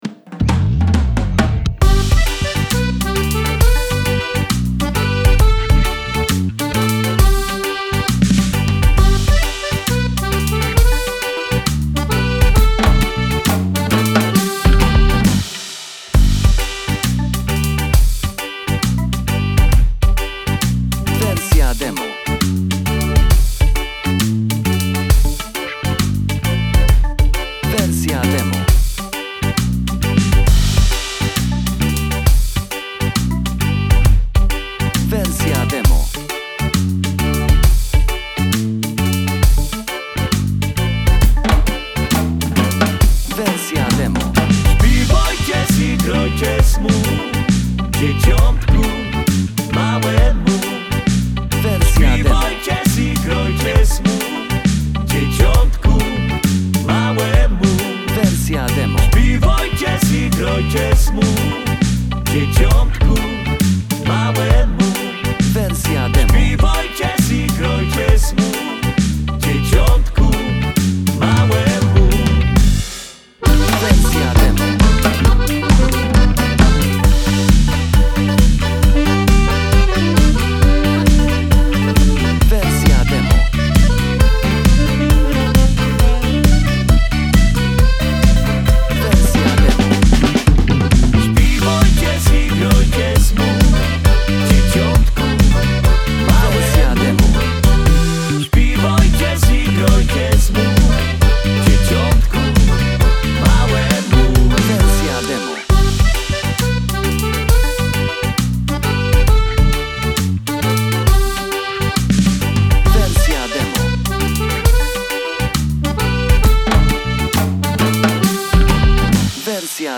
Pastorałka, kolęda instrumental, podkład
Z chórkiem nagranym w refrenie lub bez chórku.